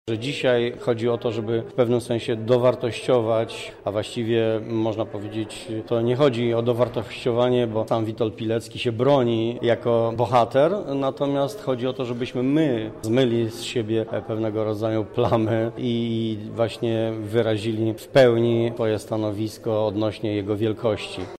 – mówi Stanisław Brzozowski, radny miasta Lublin